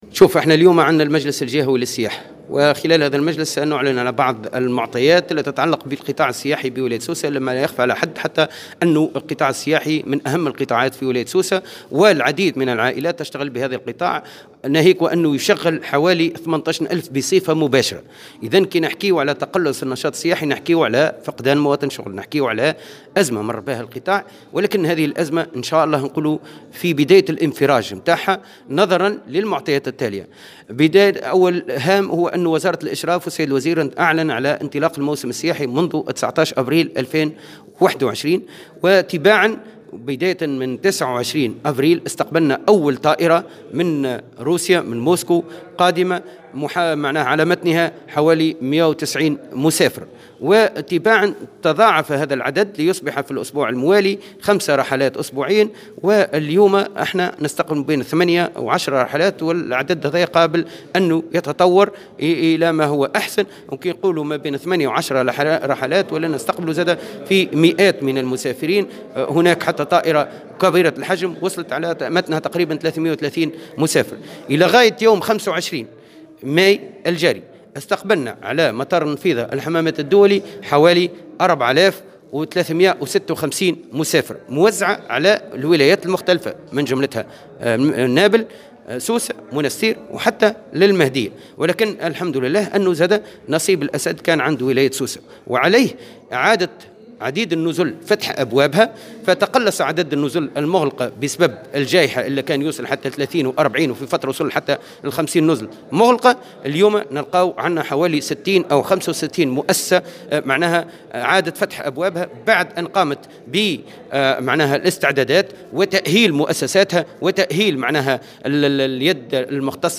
وأكد القايد في تصريح للجوهرة أف أم، اليوم الأربعاء، أن من بين الرحلات التي اسقبلها مطار النفيضة مؤخرا، طائرة كبيرة على متنها نحو 330 سائحا.